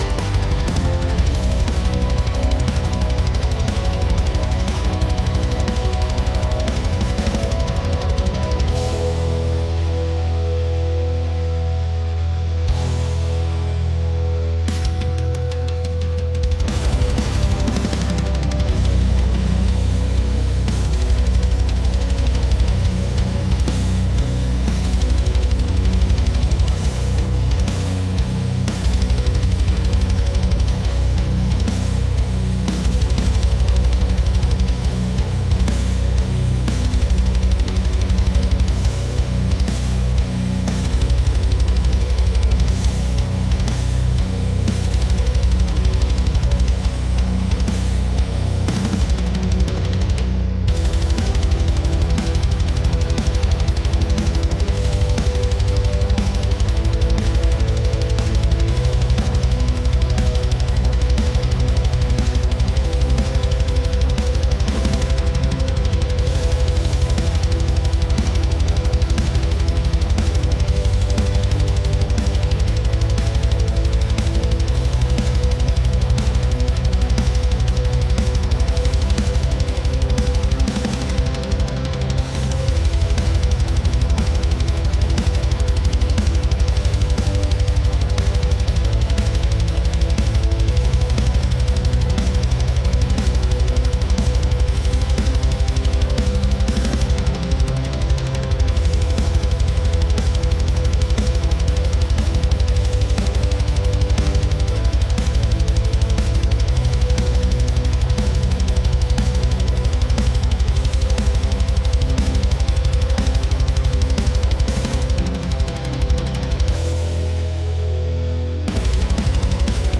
metal | intense